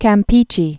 (kăm-pēchē, käm-pĕchĕ)